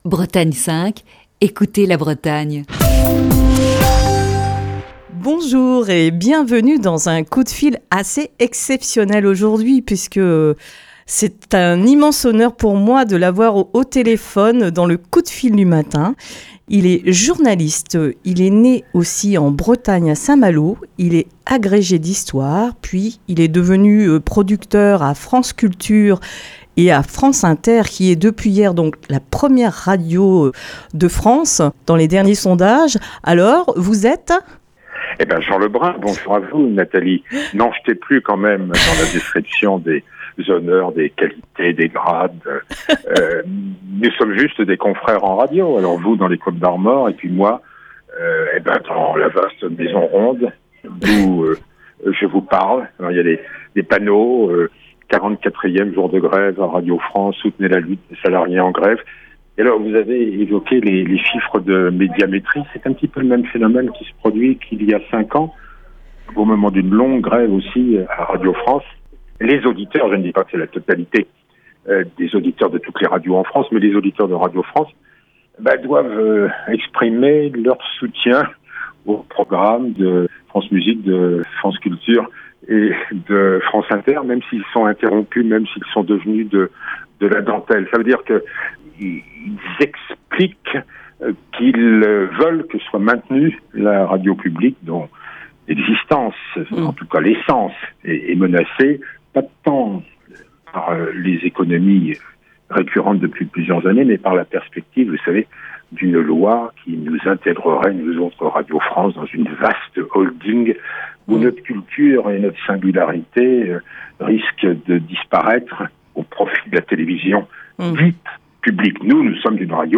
Jean Lebrun, producteur de la Marche de l'Histoire sur France Inter, est l'invité du Coup de fil du matin.